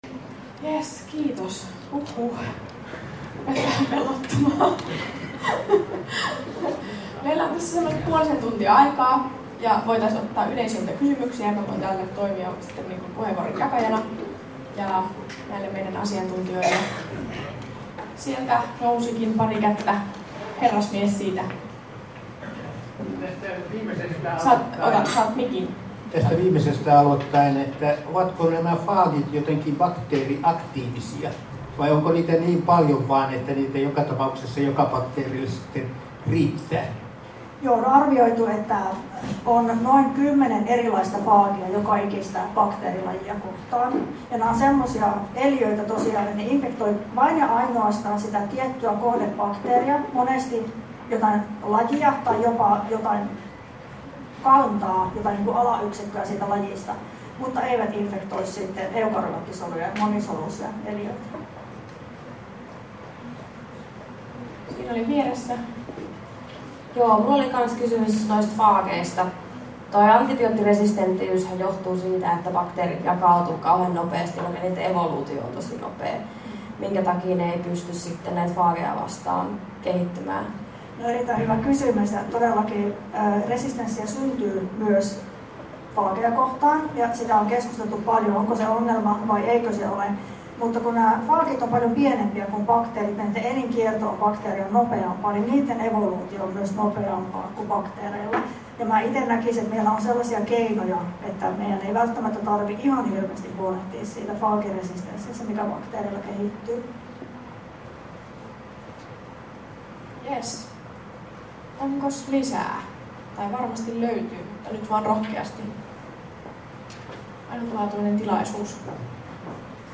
Yleisökysymykset — Moniviestin